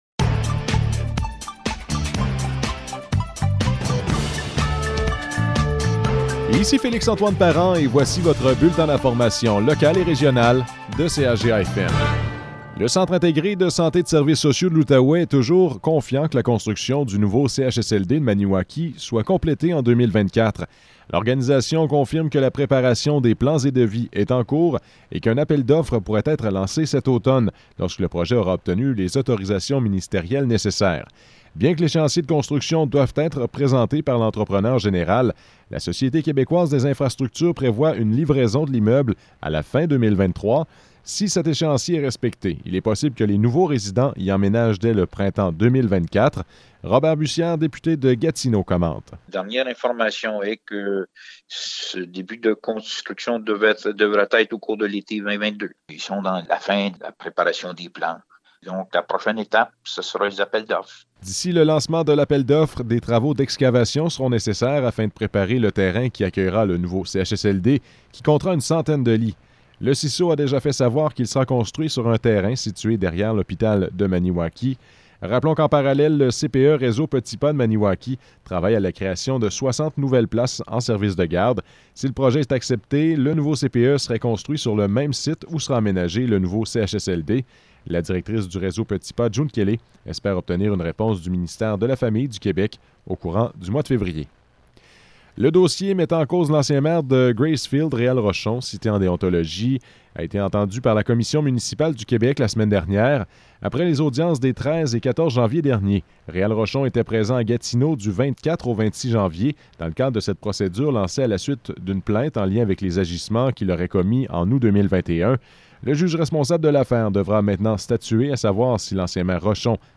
Nouvelles locales - 31 janvier 2022 - 12 h